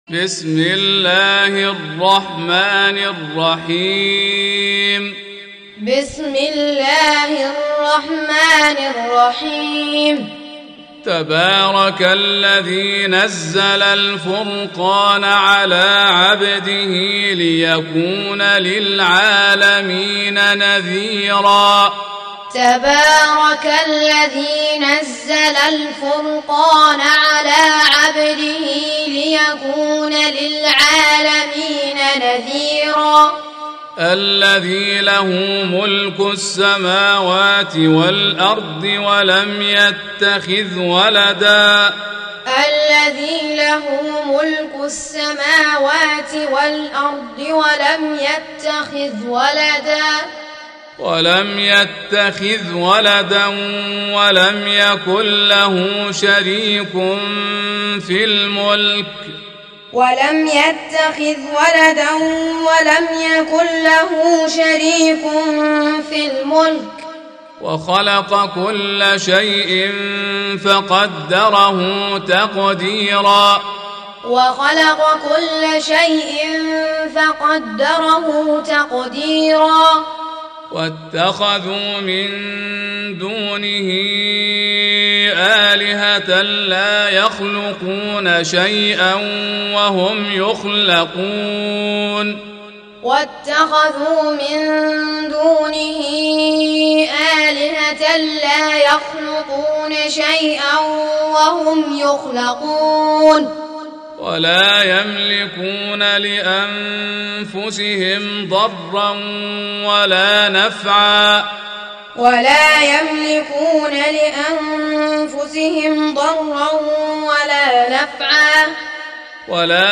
Audio Quran Taaleem Tutorial Recitation Teaching Qur'an One to One